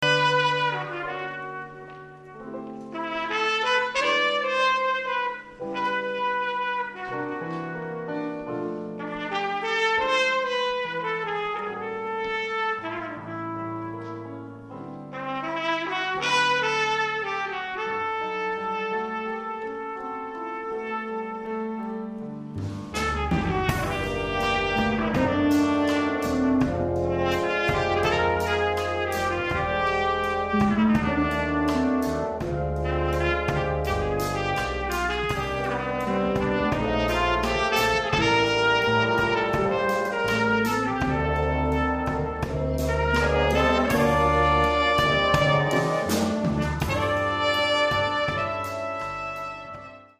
Back to normal big band line-up.
Sax line-up: 2 altos, 2 tenors, flute.